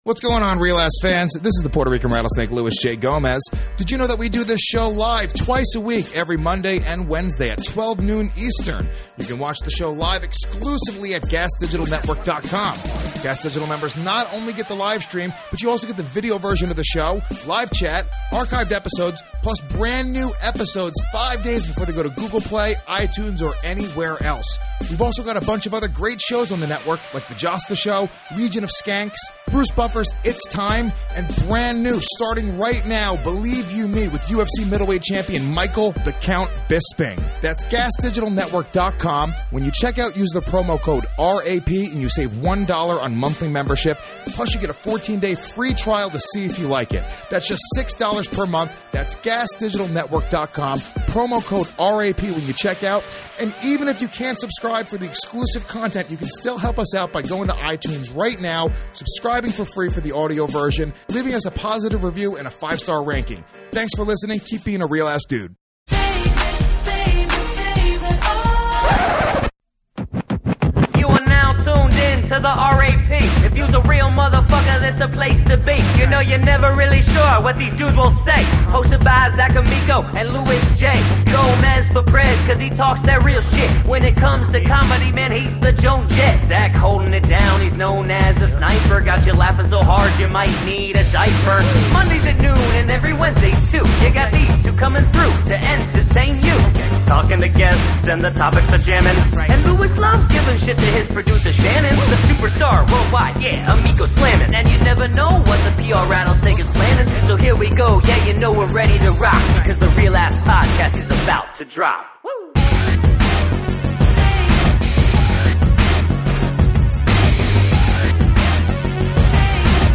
CCVIII. This is The End (Solo Show)